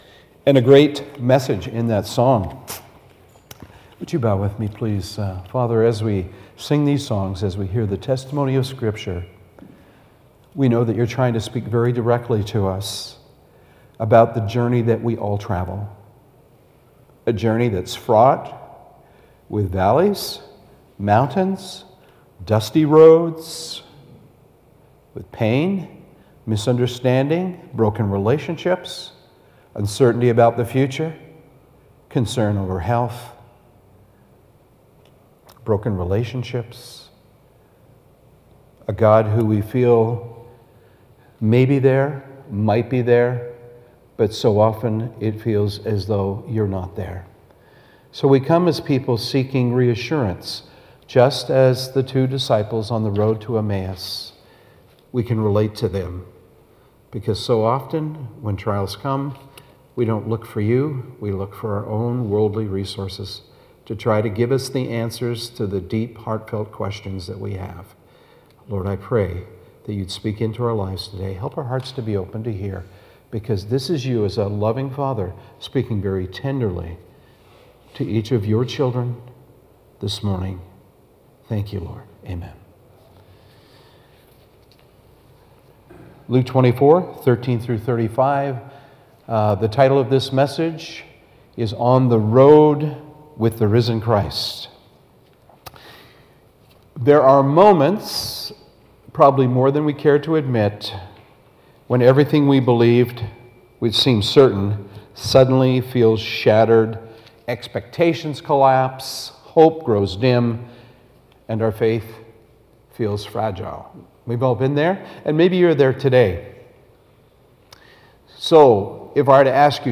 Sermons | Peninsula Mission Community Church
From this series Current Sermon On the Road with the Risen Christ The Resurrection Factor Luke 24:13-35 Guest Speaker April 12, 2026 View all Sermons in Series